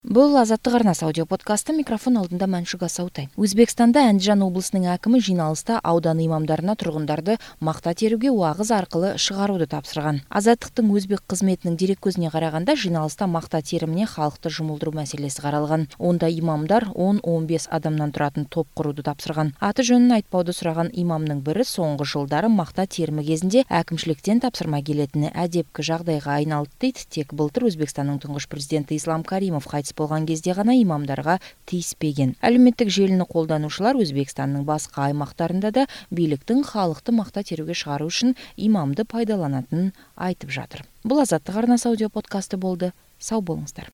Өзбекстанда Әндіжан облысының әкімі жиналыста аудан имамдарына тұрғындарды мақта теруге уағыз арқылы шығаруды тапсырған.